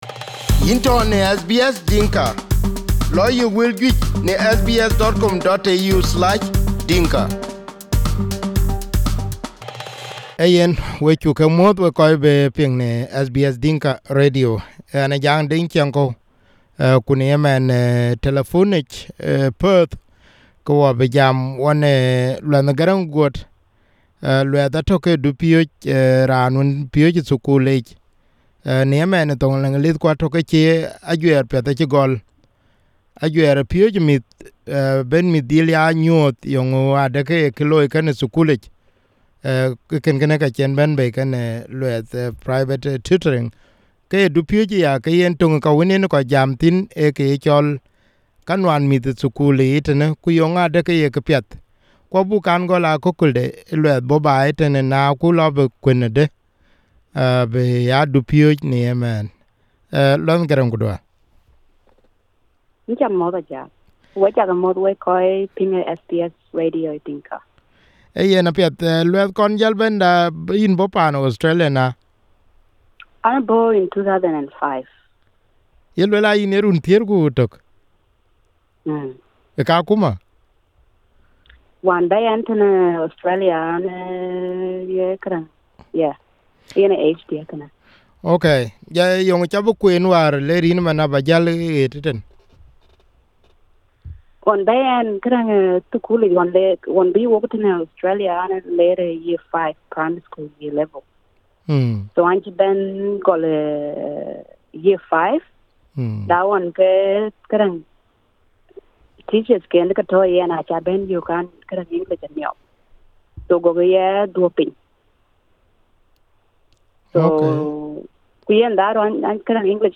This was her first interview on SBS Dinka radio